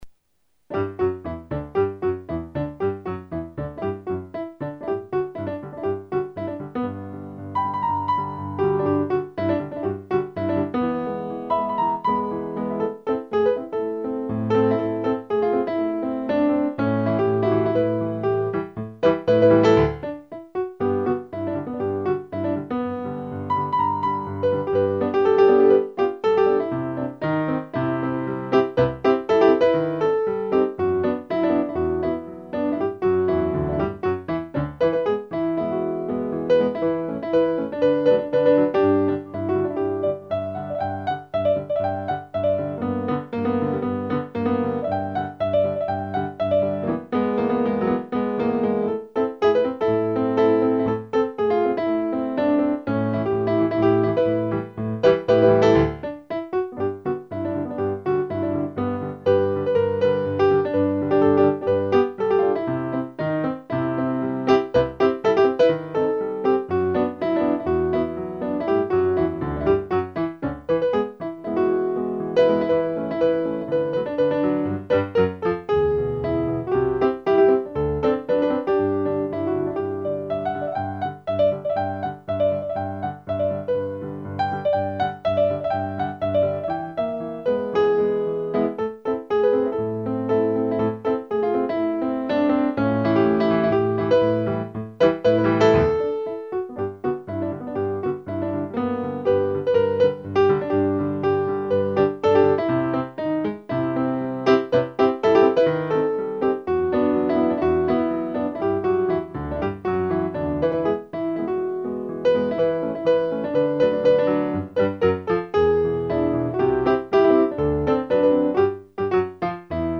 Piano live für Ihre Party